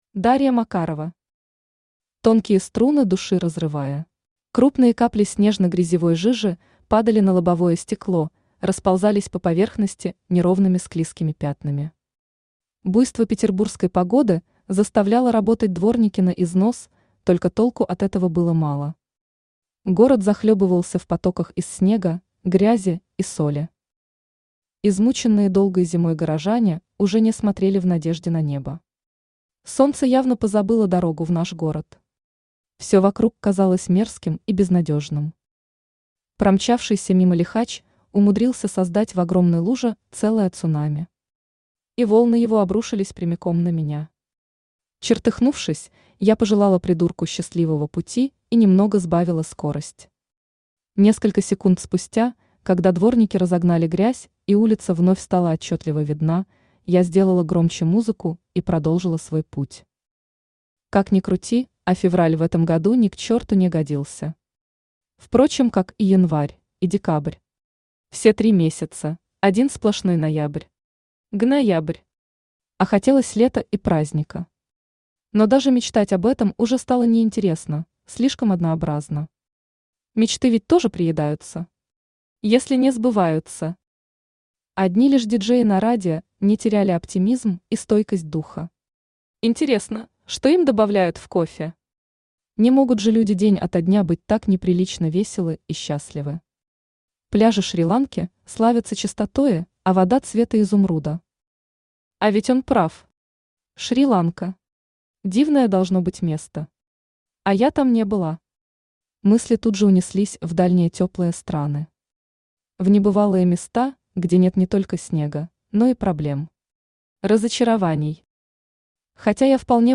Aудиокнига Тонкие струны души разрывая Автор Дарья Макарова Читает аудиокнигу Авточтец ЛитРес.